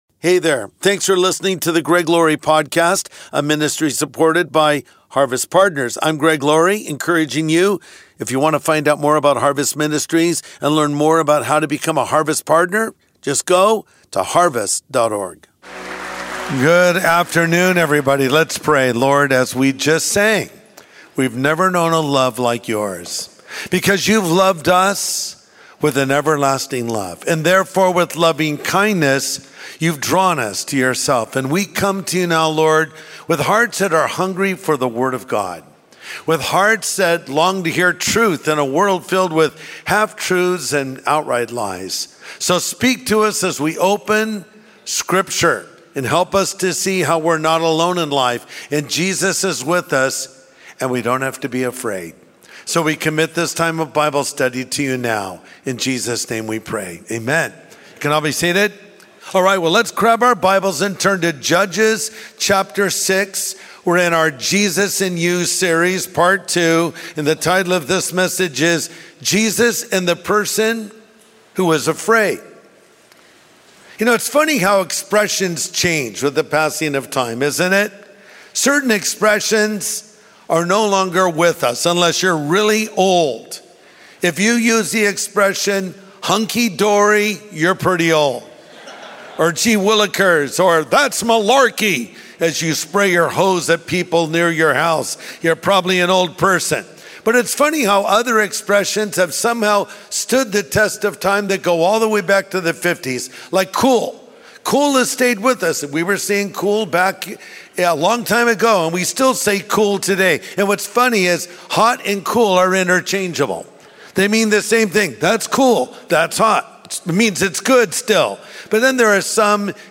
Jesus and the Person Who Is Afraid | Sunday Message Podcast with Greg Laurie